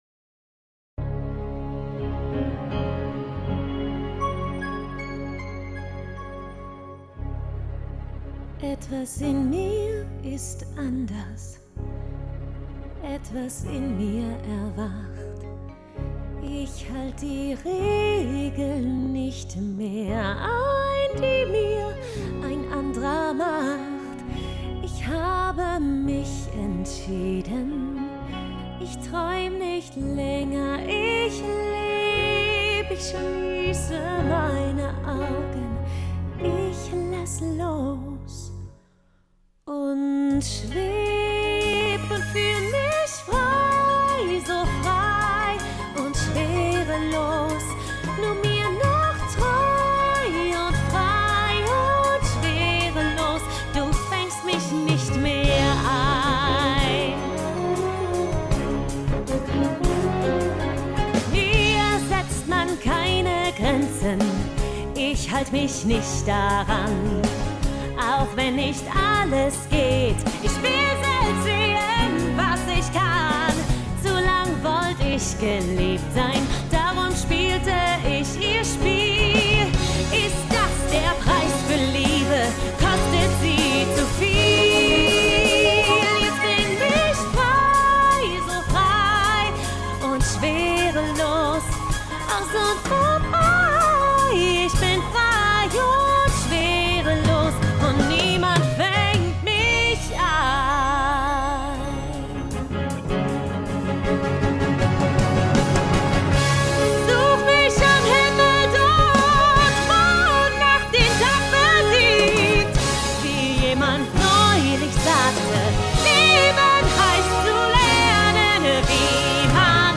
Vocals (Gesang)